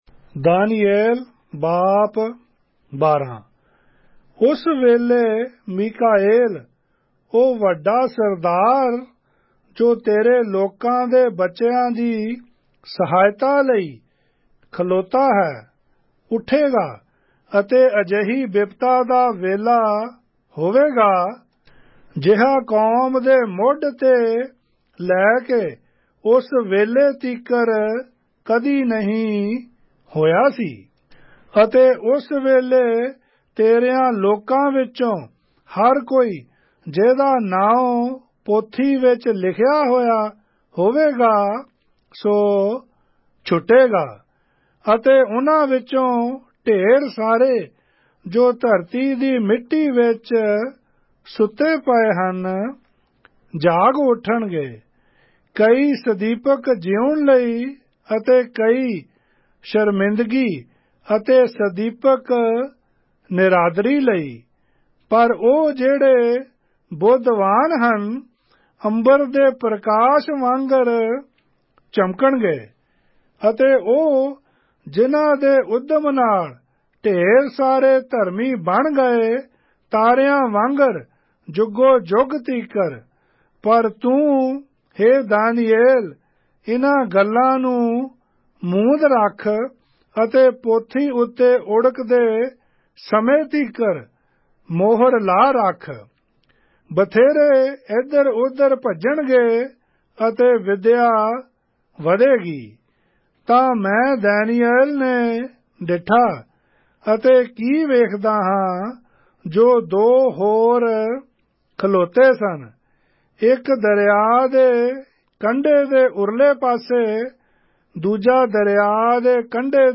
Punjabi Audio Bible - Daniel 6 in Alep bible version